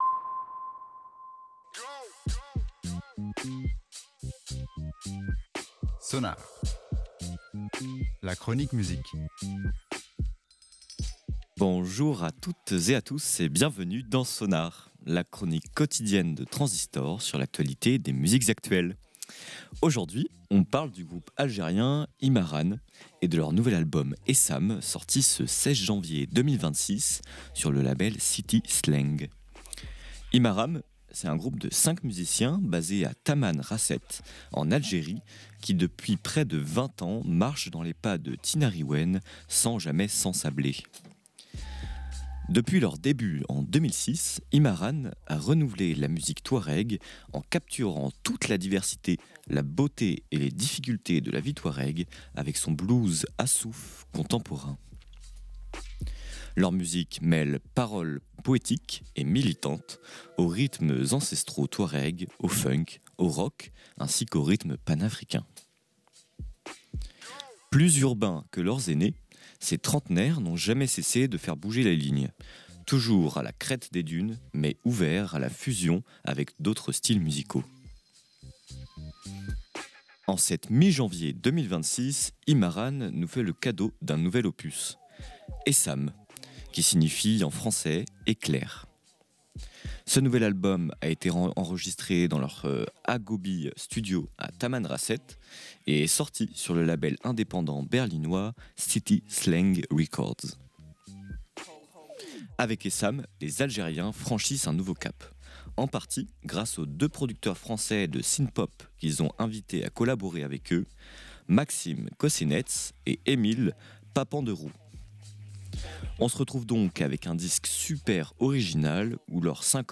Leur musique mêle paroles poétiques et militantes aux rythmes ancestraux Touaregs, au funk, au rock et aux rythmes panafricains.